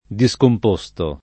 [ di S komp 1S to ]